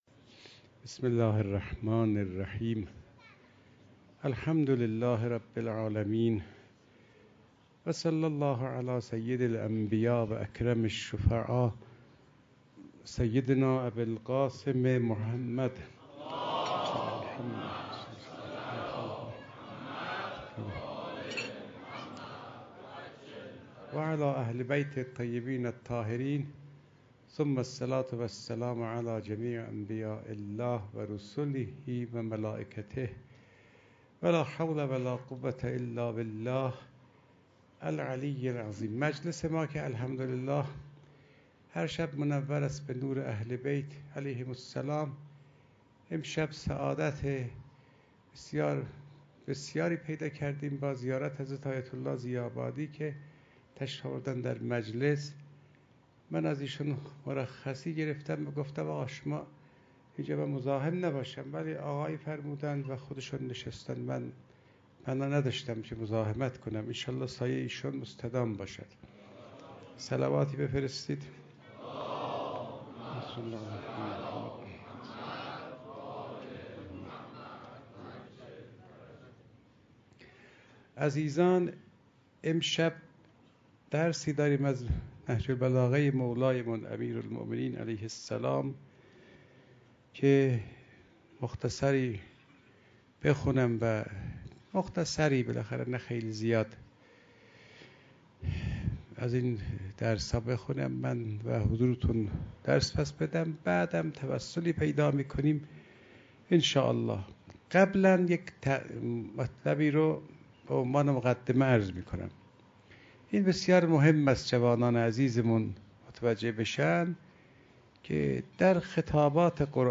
شب هفتم محرم95_سخنرانی